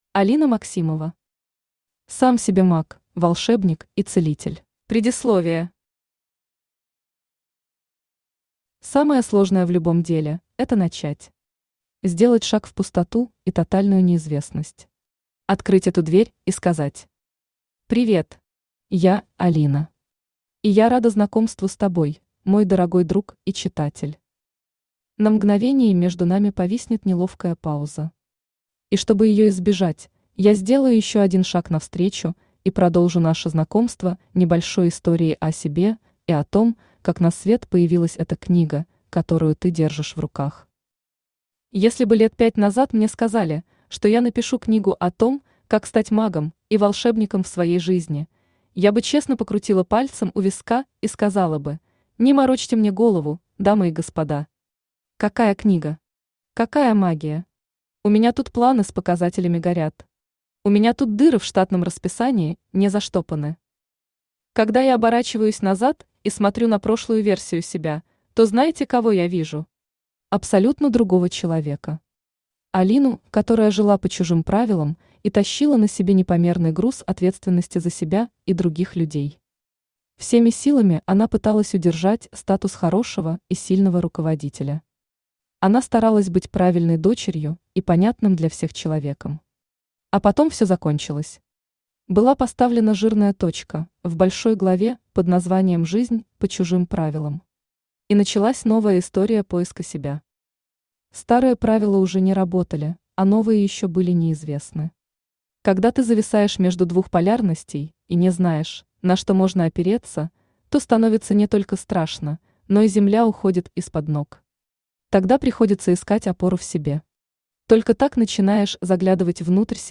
Аудиокнига Сам себе Маг, Волшебник и Целитель | Библиотека аудиокниг
Aудиокнига Сам себе Маг, Волшебник и Целитель Автор Алина Максимова Читает аудиокнигу Авточтец ЛитРес.